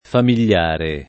familiare [familL#re] o famigliare [